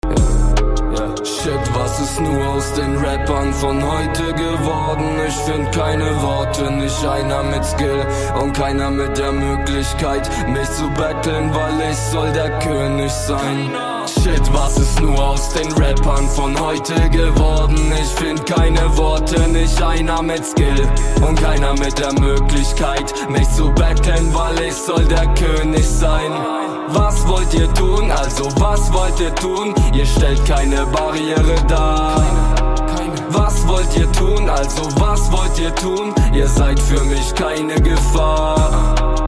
Ich nehme hin und wieder in einem Studio auf von dem mir der Sound sehr gut gefällt, hauptsächlich Deutschrap.
Aber mir fällt auf, dass gerade in den tiefen Mitten etwas besonderes gemacht wurde idk.